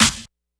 Snare_36.wav